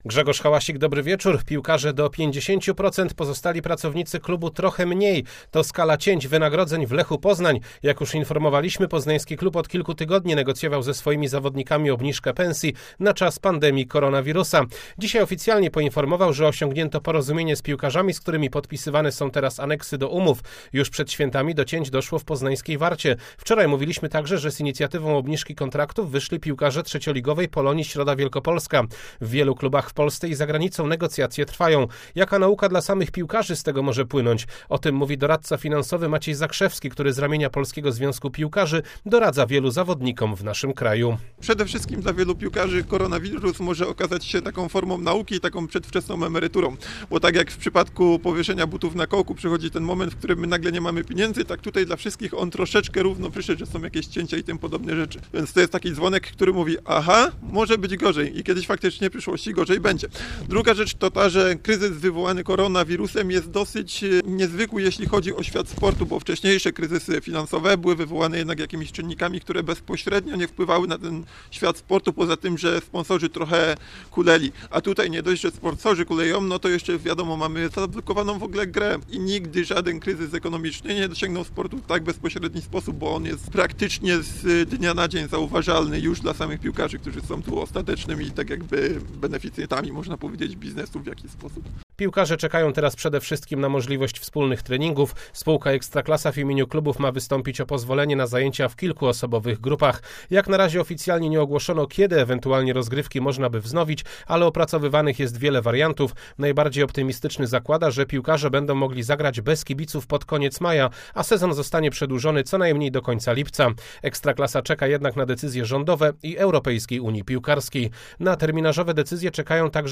16.04. SERWIS SPORTOWY GODZ. 19:05